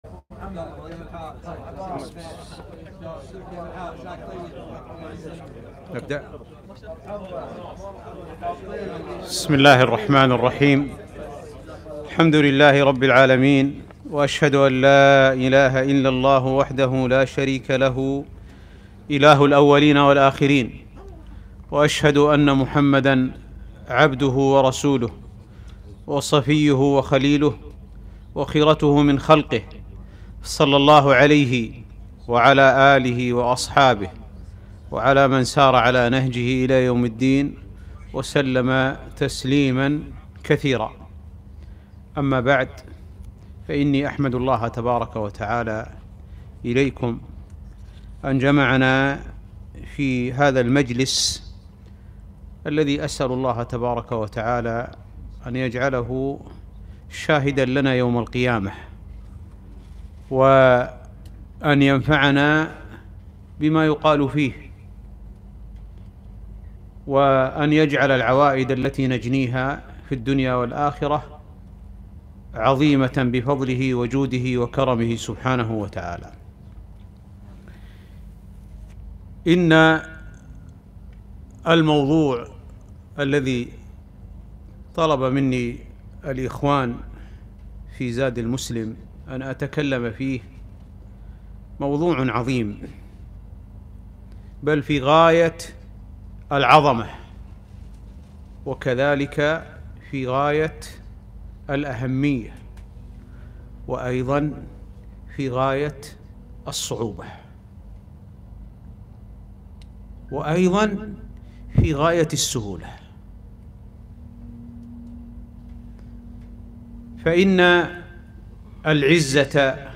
محاضرة - أسباب العزة والتمكين